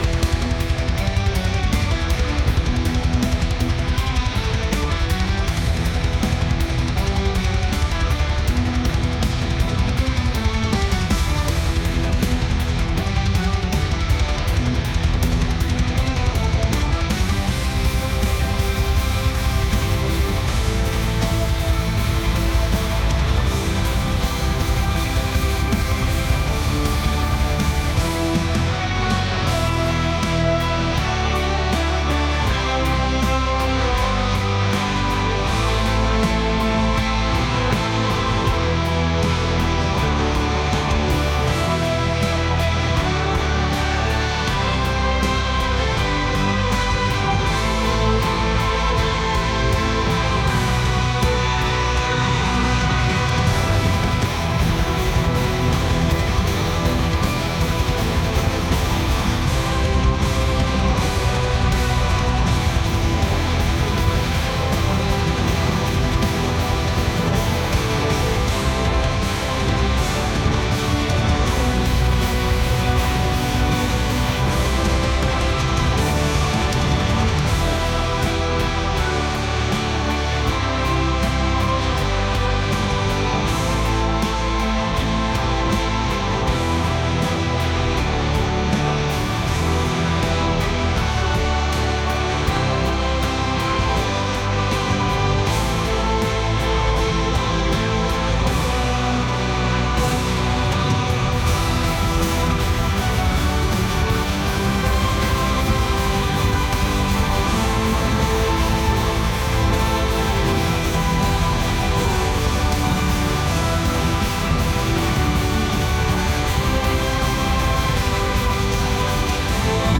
rock | ambient